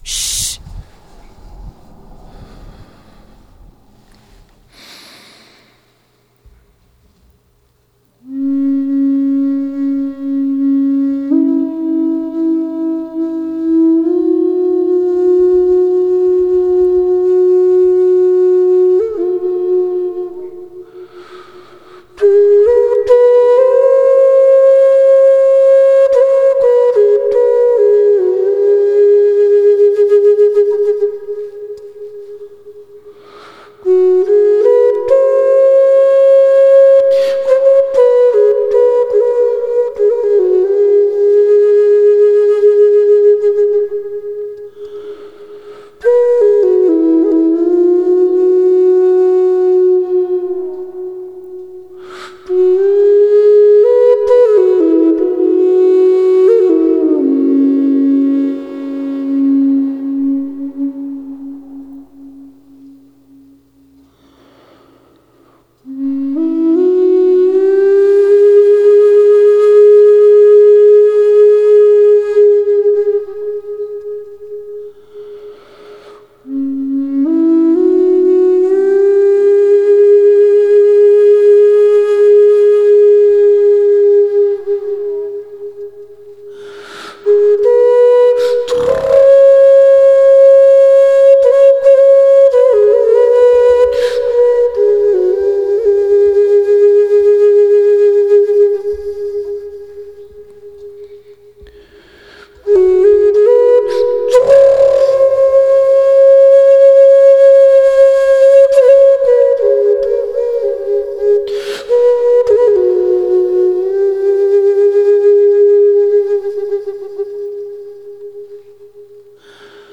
Posted in spirituality Tags: Celtic, contemplation, earth music, meditation, Native American, peace pipe, trees, tribal flute, Turtle Island